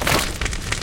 PixelPerfectionCE/assets/minecraft/sounds/mob/magmacube/small3.ogg at mc116